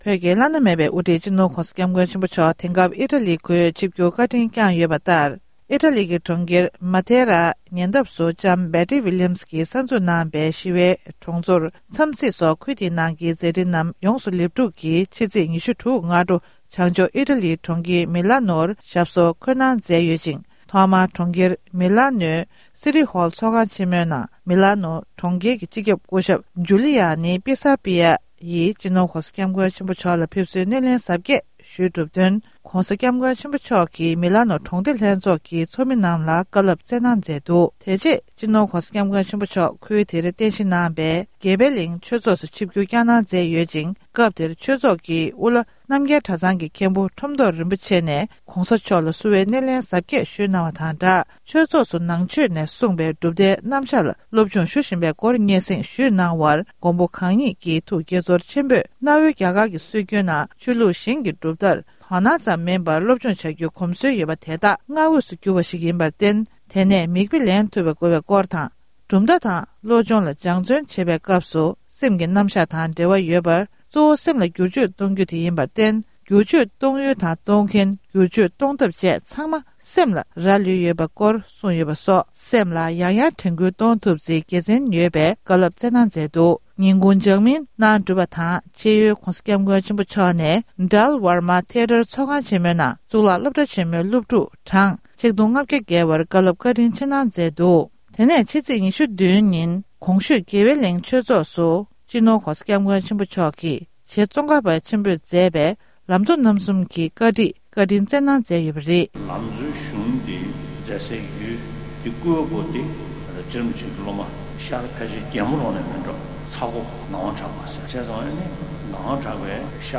༸གོང་ས་མཆོག་ནས་ཨི་ཊ་ལིའི་དགེ་འཕེལ་གླིང་དུ་ལམ་གཙོ་རྣམ་གསུམ་གྱི་བཀའ་ཆོས་སྩལ་བ།